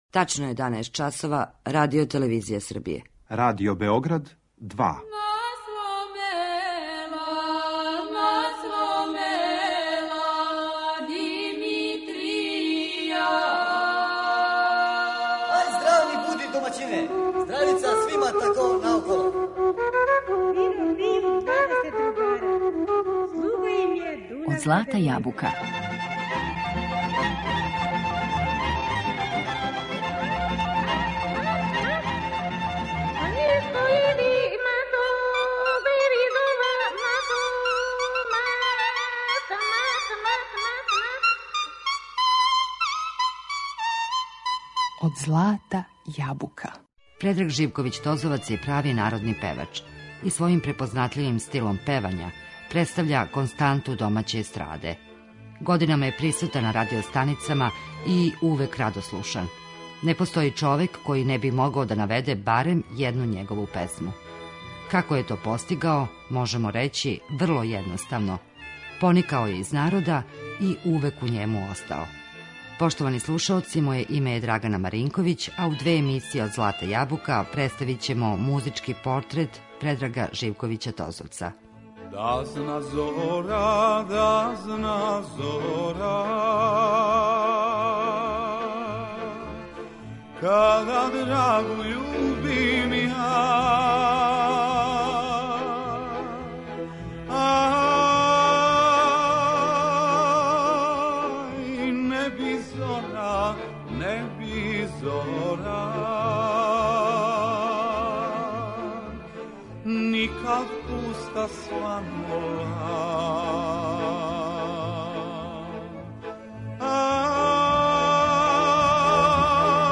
Гост - Предраг Живковић Тозовац